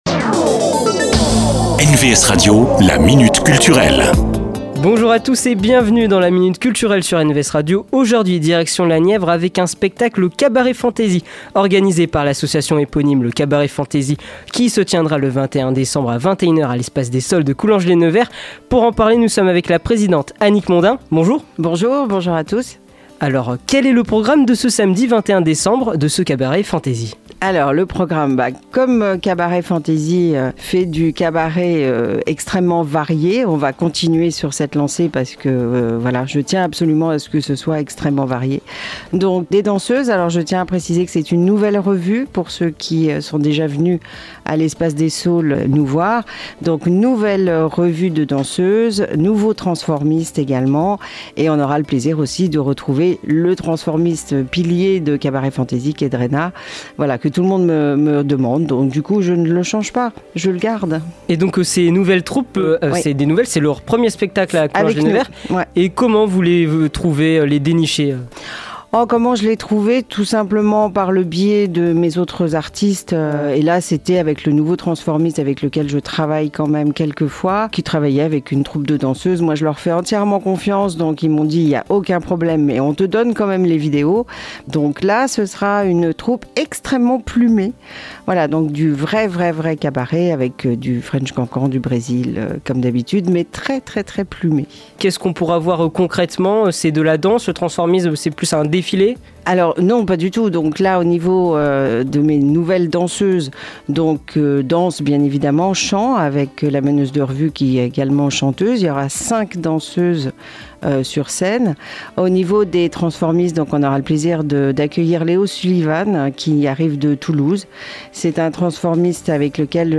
La Minute Culture, rencontre avec les acteurs culturels de la région.